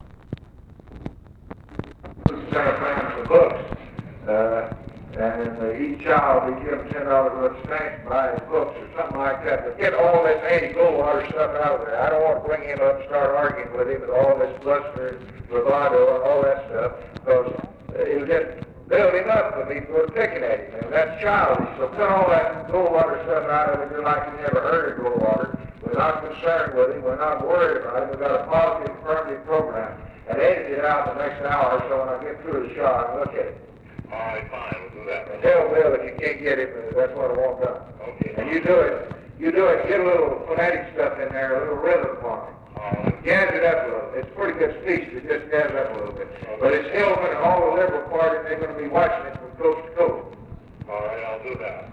LBJ INSTRUCTS UNIDENTIFIED MALE (RICHARD GOODWIN?) TO REMOVE REFERENCES TO BARRY GOLDWATER FROM SPEECH DRAFT
Conversation with (possibly) RICHARD GOODWIN, June 5, 1964
Secret White House Tapes